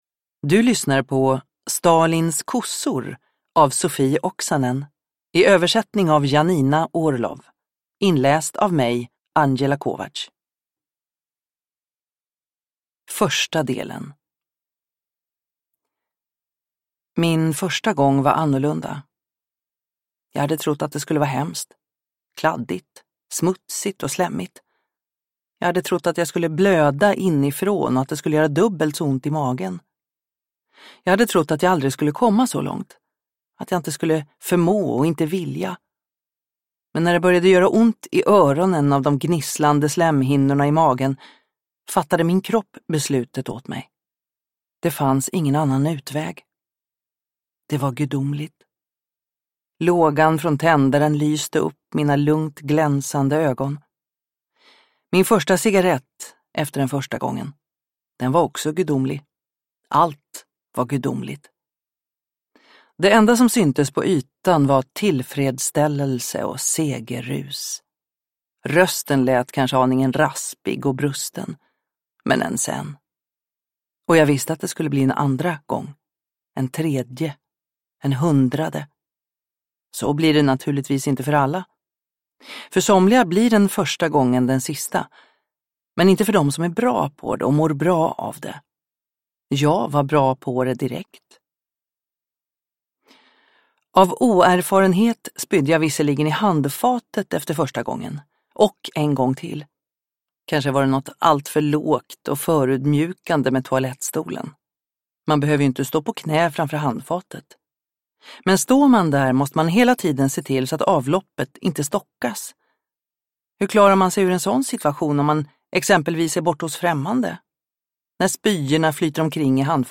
Stalins kossor – Ljudbok – Laddas ner